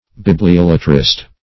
Search Result for " bibliolatrist" : The Collaborative International Dictionary of English v.0.48: Bibliolater \Bib`li*ol"a*ter\ (b[i^]b`l[i^]*[o^]l"[.a]*t[~e]r), Bibliolatrist \Bib`li*ol"a*trist\ (b[i^]b`l[i^]*[o^]l"[.a]*tr[i^]st), n. [See.
bibliolatrist.mp3